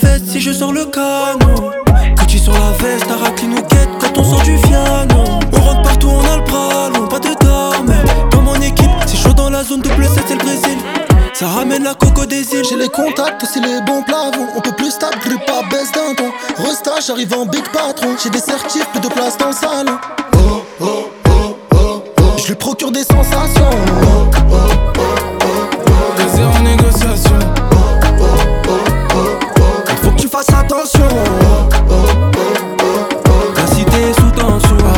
Жанр: Иностранный рэп и хип-хоп / Рэп и хип-хоп